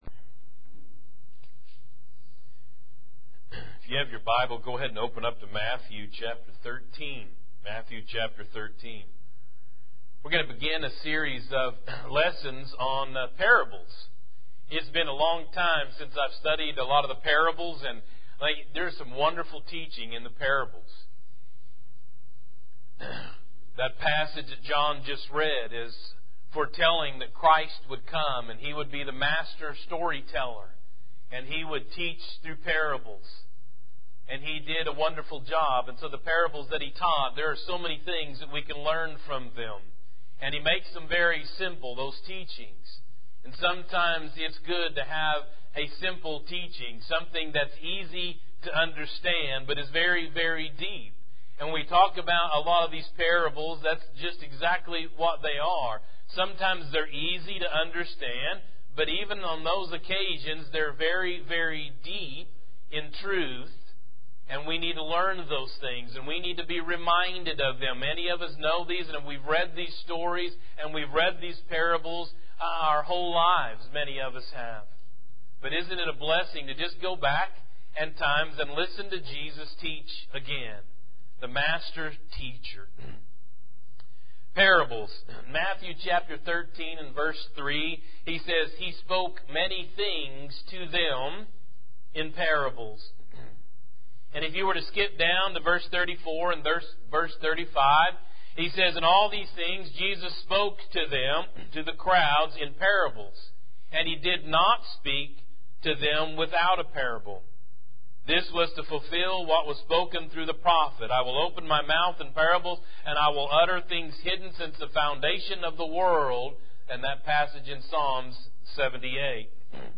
More Audio Sermons